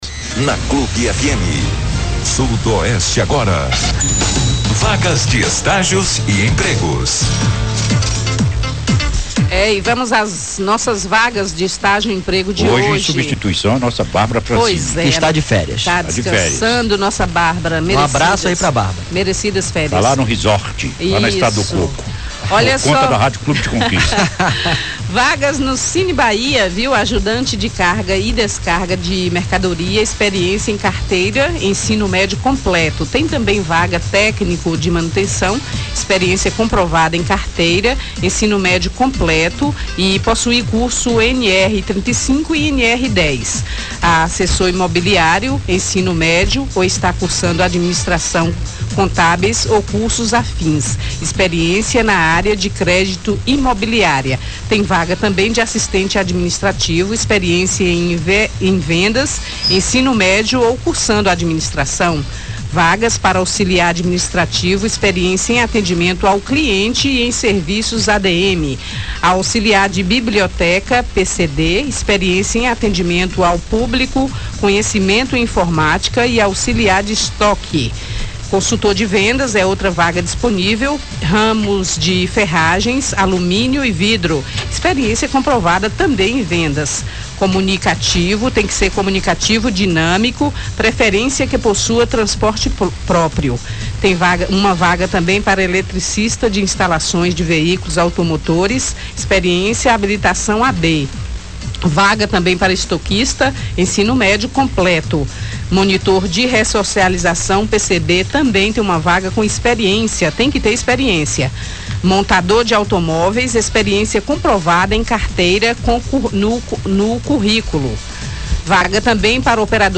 Entrevista à Rádio Clube de Conquista: com R$ 6 milhões, Paulo Magalhães vai revitalizar o Parque Lagoa das Bateias
A promessa do comunista aconteceu durante entrevista ao Jornal da Cidade, na Rádio Clube de Conquista, nesta segunda-feira (15). Segundo o vereador comunista, as intervenções devem começar ainda neste ano, isso a depender de um projeto que deverá ser apresentado pela Prefeitura de Vitória da Conquista.